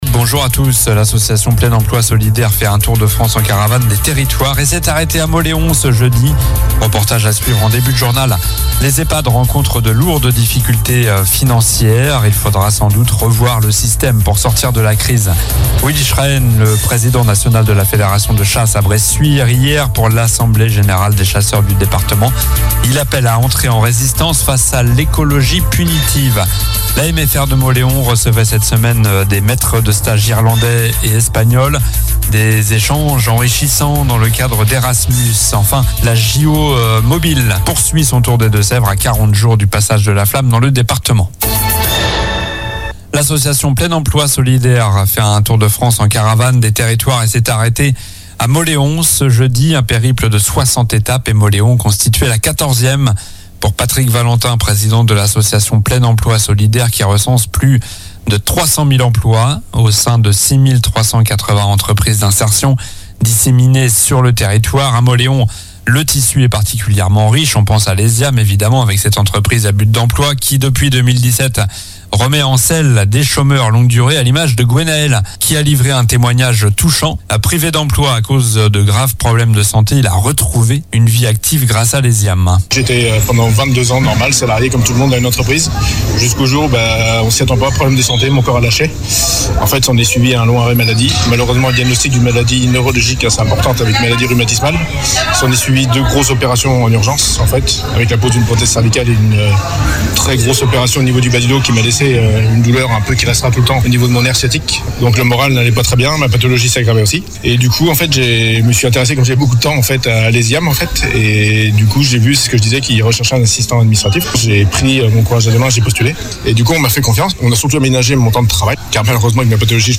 Journal du vendredi 19 avril (midi)